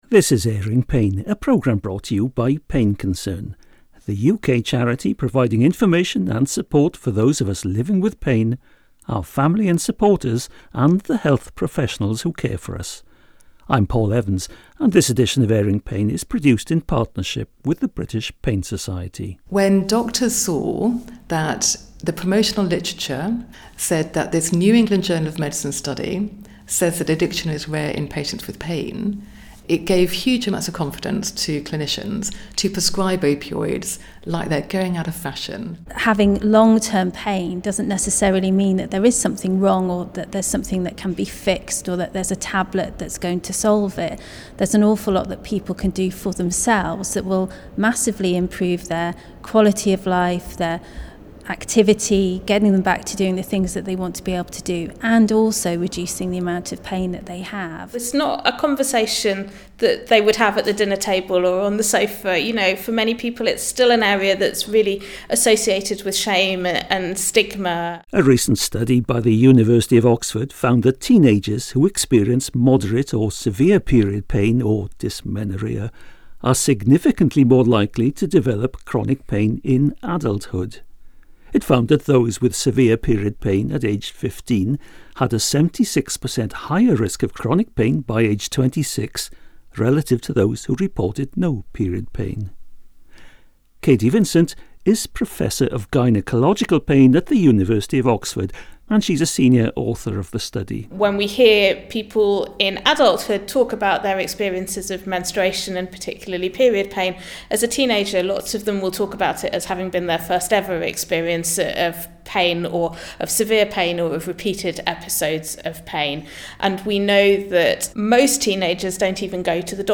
These interviews were recorded at their 2025 Annual Scientific Meeting.